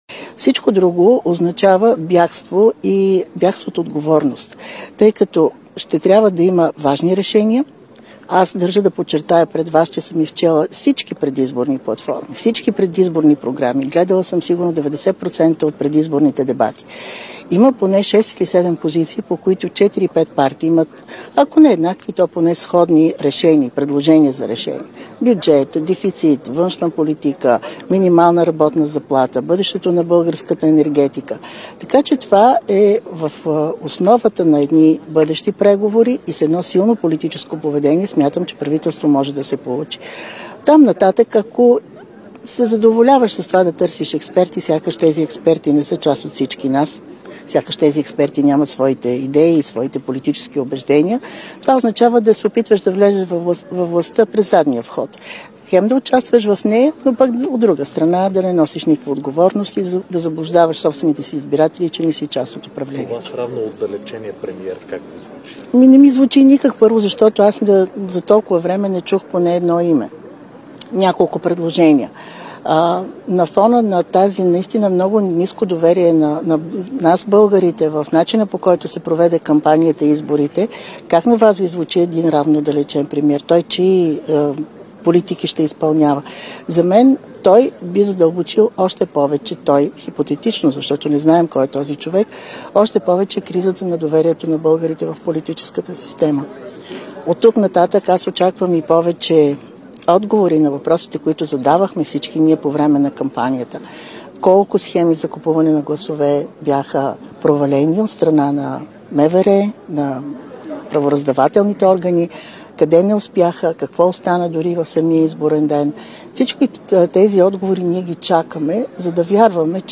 Директно от мястото на събитието